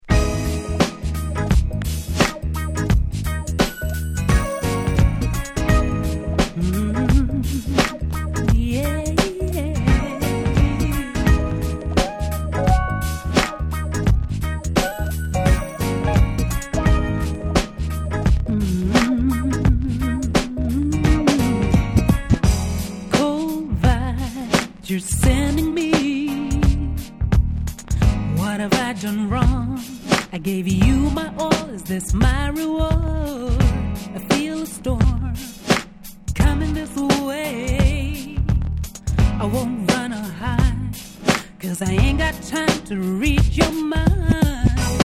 Nice UK R&B♩
めちゃくちゃ爽やか。